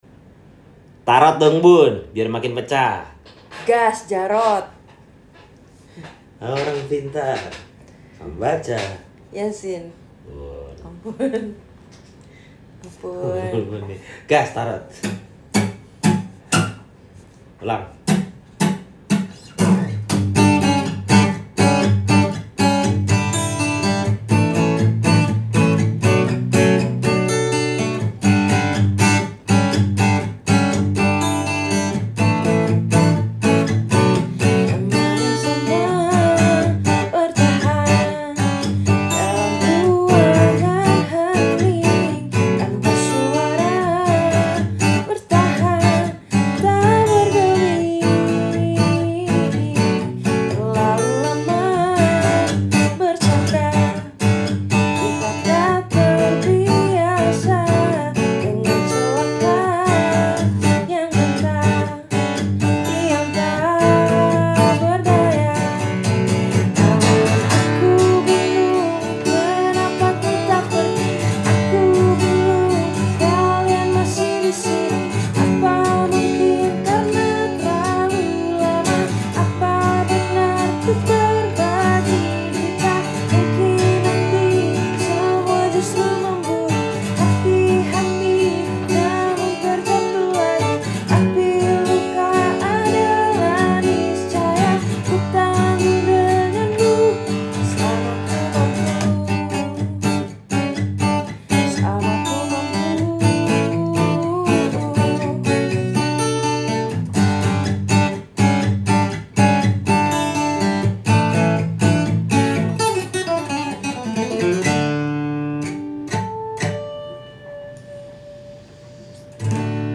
Versi Akustik.
Gitar